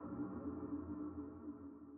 sonarTailAirFarShuttle2.ogg